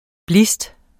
Udtale [ ˈblisd ]